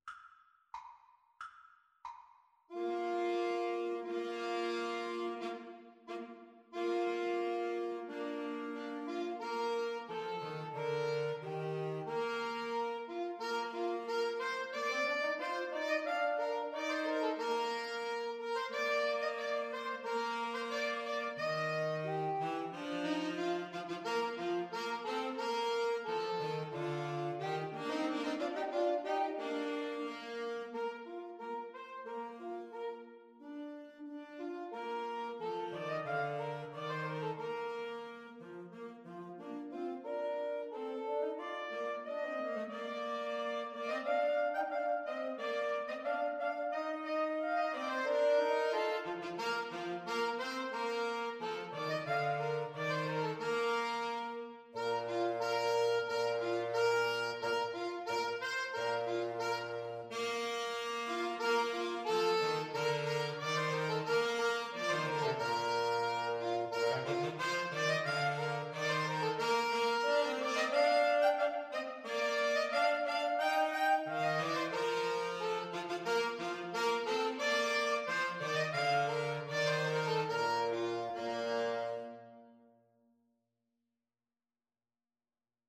Soprano SaxophoneAlto SaxophoneTenor Saxophone
Bb major (Sounding Pitch) (View more Bb major Music for Woodwind Trio )
March ( = c. 90)